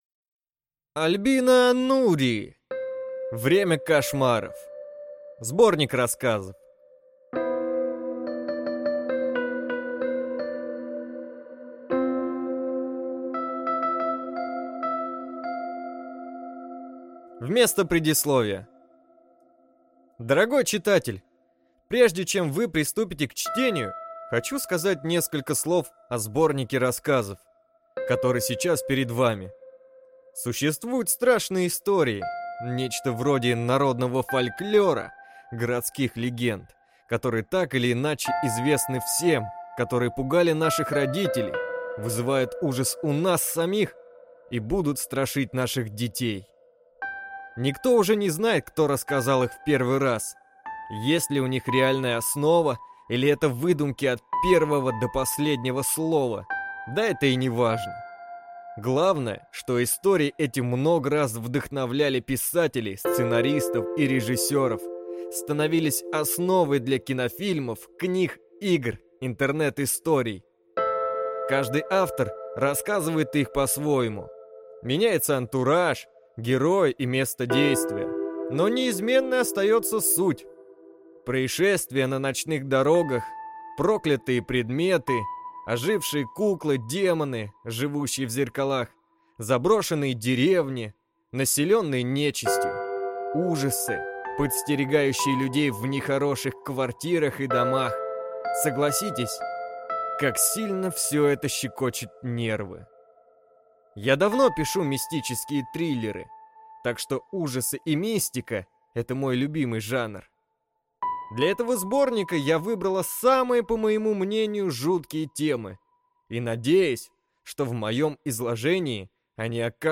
Аудиокнига Время кошмаров | Библиотека аудиокниг
Прослушать и бесплатно скачать фрагмент аудиокниги